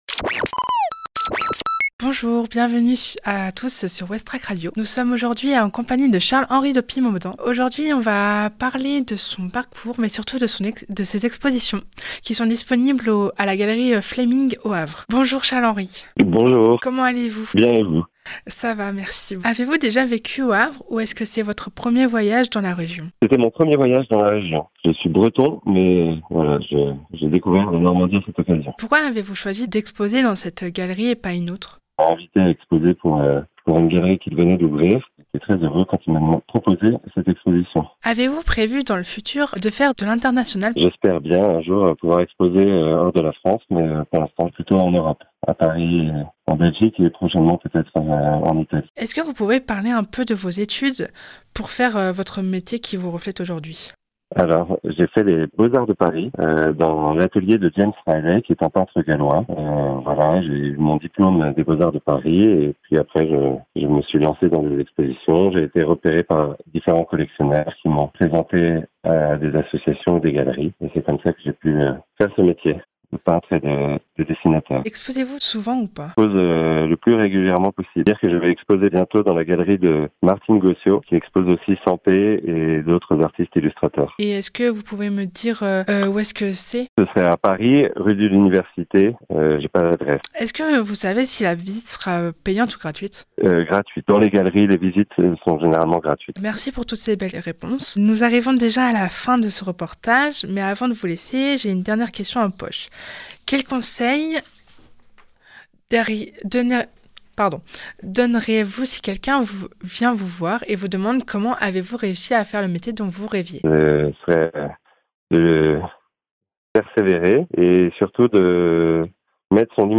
A travers ce reportage